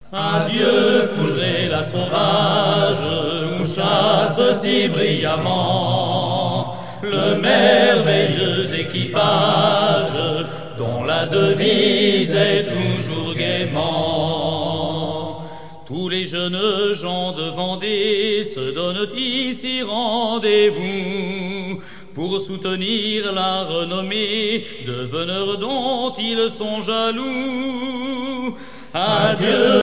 circonstance : rencontre de sonneurs de trompe
Pièce musicale éditée